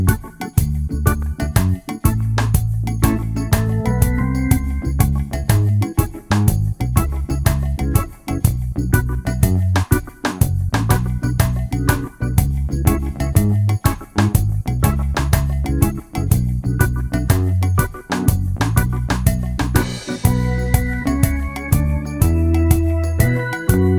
minus guitars no Backing Vocals Reggae 3:59 Buy £1.50